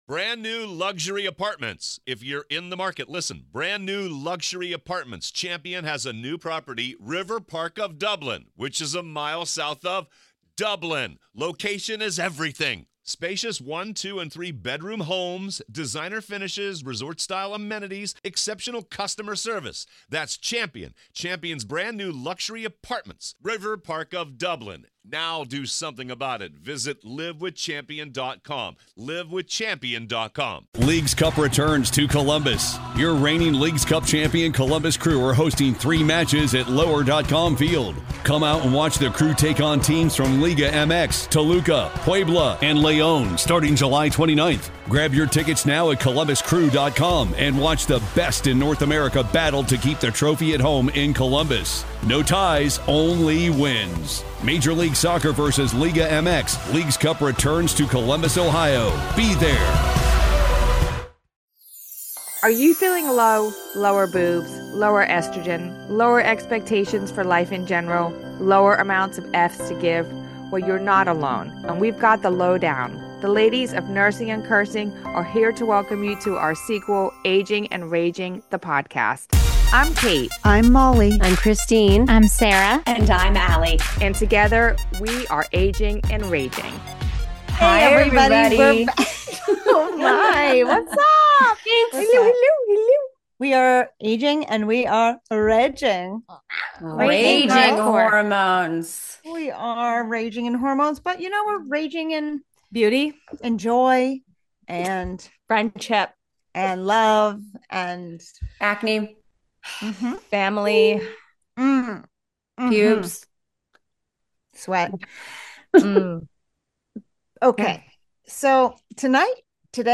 This week the ladies discuss what we do and don’t do to feel good in our skin. Moms, how far do you go to tweak your appearance?